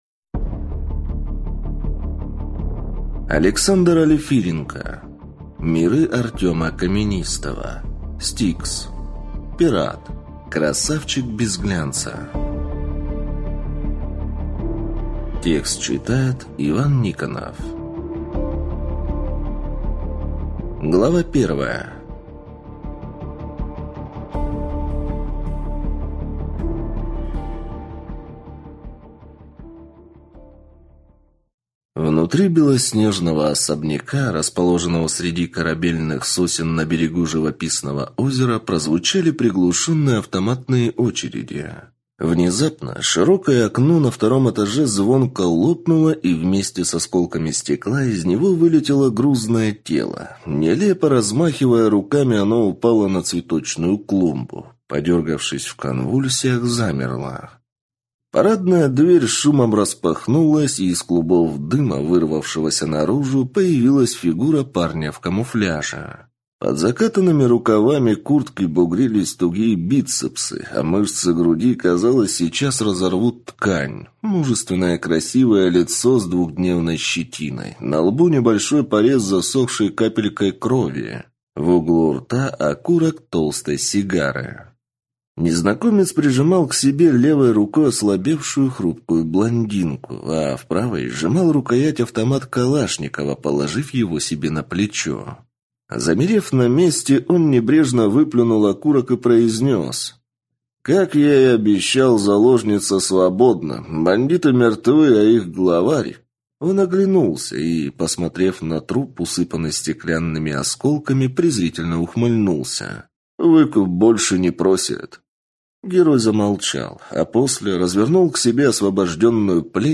Аудиокнига Миры Артёма Каменистого. S-T-I-K-S. Пират. Красавчик без глянца | Библиотека аудиокниг